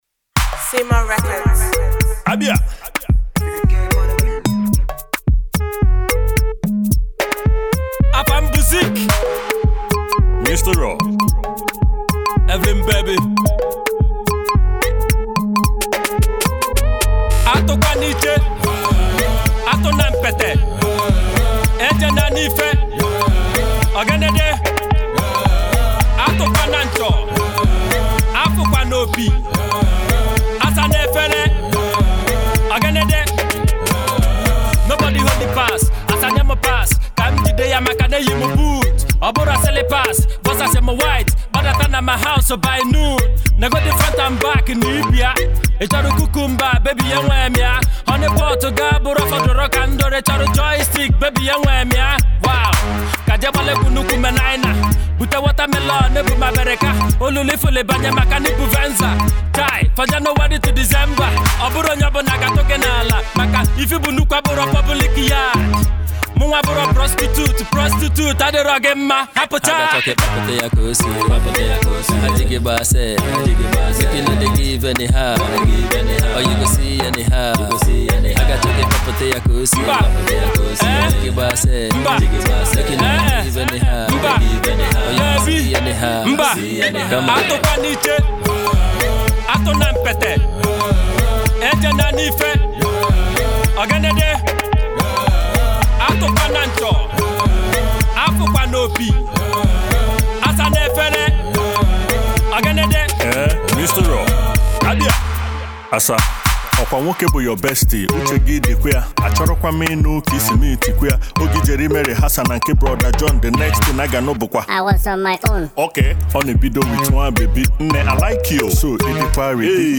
indigenous rapper
Igbo rap
heavy flows to compliment the groovy sound